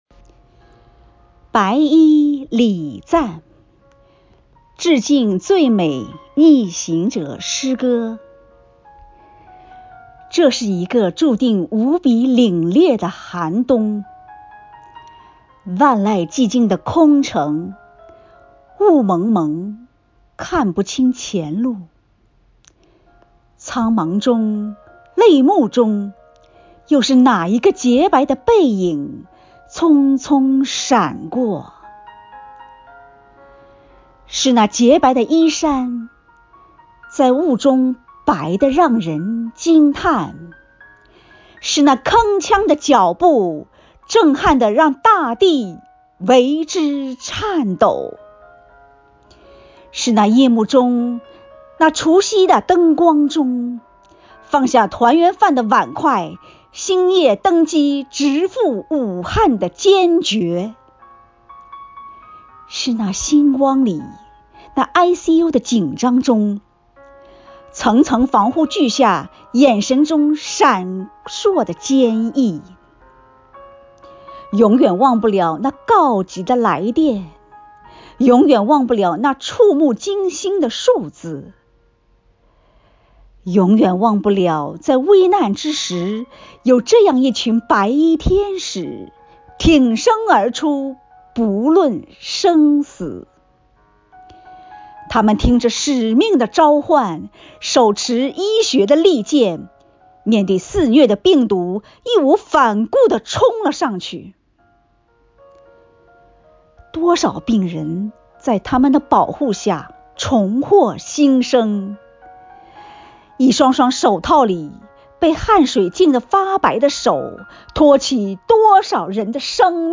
为赞美“逆行英雄”甘于奉献、大爱无疆的崇高精神，女教师们精心创作或挑选朗诵诗歌作品，用饱含深情的声音歌颂和致敬奋斗在一线的抗“疫”英雄们，讴歌了中华民族的伟大和坚韧，表达了对祖国和武汉的美好祝福，热切盼望疫情过后的春暖花开。
附件：一、抗“疫”事迹诗朗诵选登